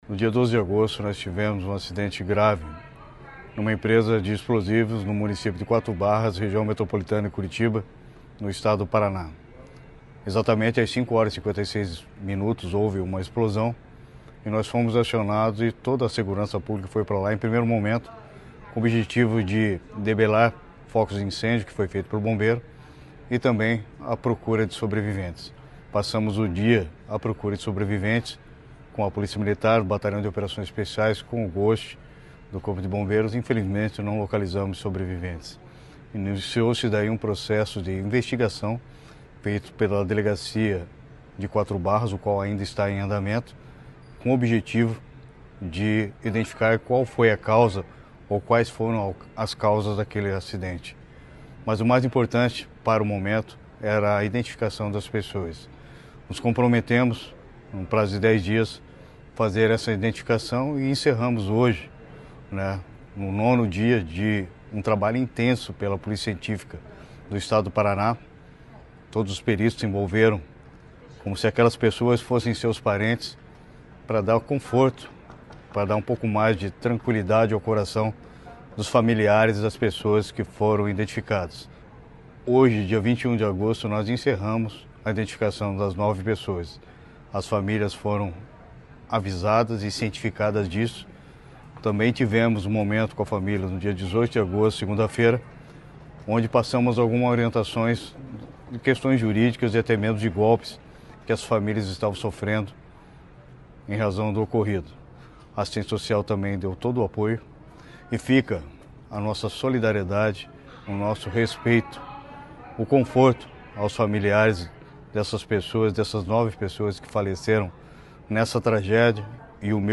Sonora do secretário da Segurança Pública, Hudson Leôncio Teixeira, sobre a conclusão da identificação das vítimas da explosão em Quatro Barras